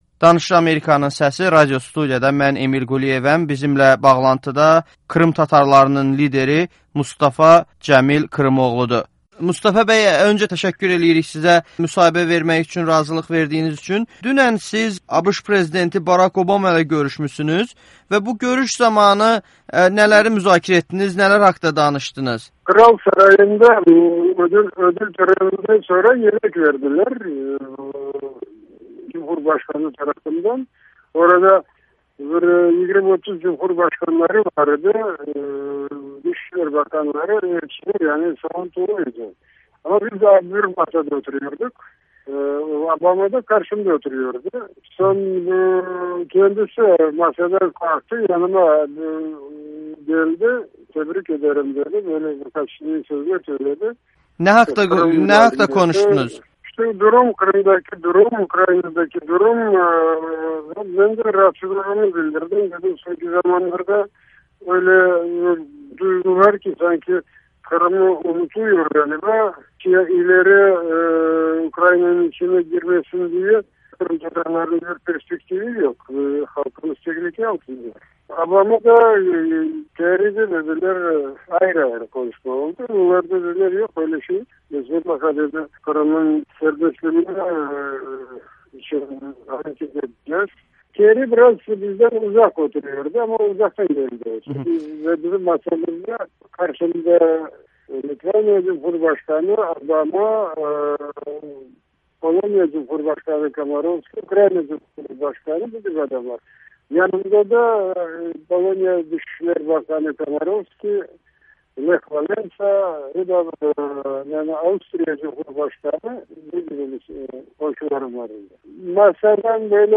Krım tatarlarının lideri Mustafa Cemil Krımoğlu ABŞ prezidenti ilə görüşü barədə Amerikanın Səsinə müsahibə verib